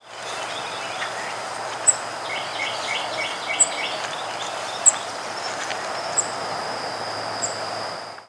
Cape May Warbler diurnal flight calls
Diurnal calling sequences: